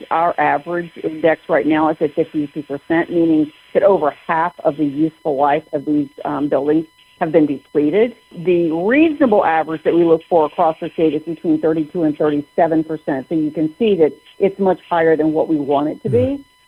A recent report from the Maryland Comptroller’s office showed that only 20% of public school buildings in the state were rated “like new” while Allegany and Garrett were among five counties with buildings well past their lifespan. State School Superintendent Dr. Carey Wright told reporters the state average indicates a need to prioritize repairs and updates…